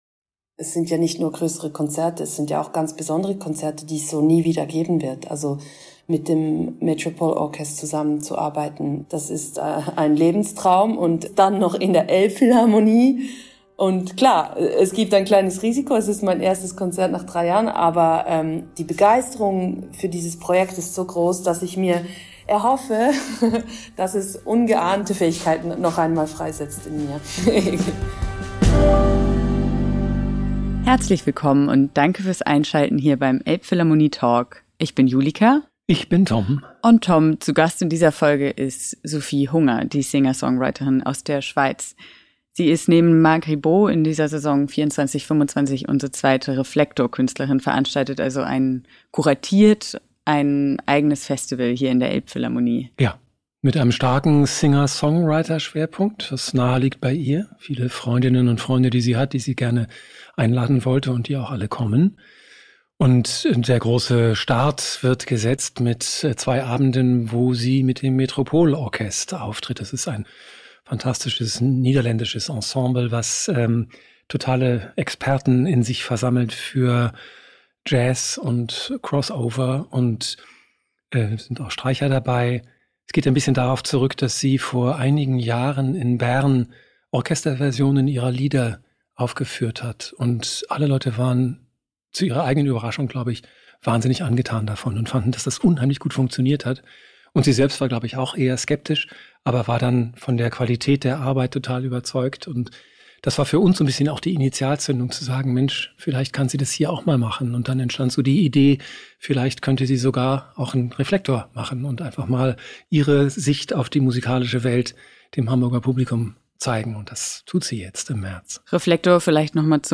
Zwei Wochen vor dem Start erzählt die redegewandte und ungemein warmherzige Künstlerin im »Elbphilharmonie Talk« darüber, wie es ist, zurückzukehren nach so langer Zeit. Über jeden einzelnen Gast des von ihr kuratierten Festivals spricht sie mit so viel Respekt und Liebe, dass ihre Vorfreude noch das trägste Gemüt in Wallung versetzen müsste.
Schon aus dem Klang ihrer Sprechstimme wird spürbar, was für eine beseelte und schöpferische Person diese Sängerin, Gitarristin und Textdichterin ist, die in so vielen Sprachen singt.